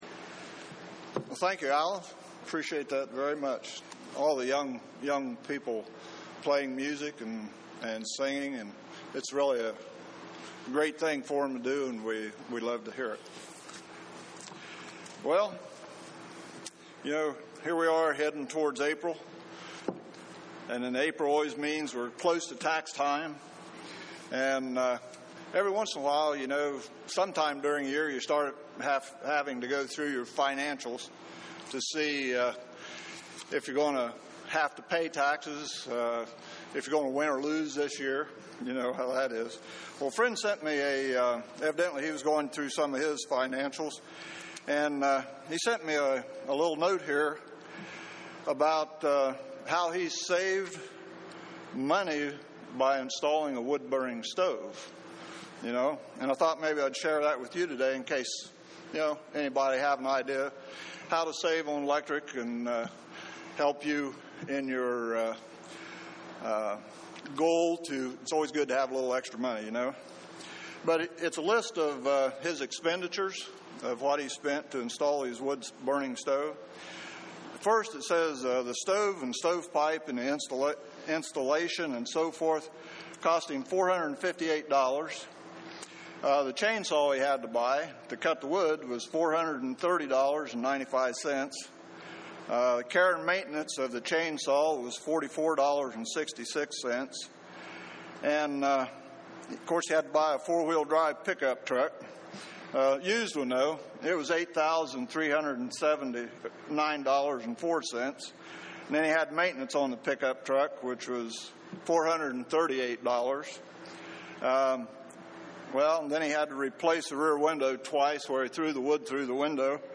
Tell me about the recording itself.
Given in Columbus, OH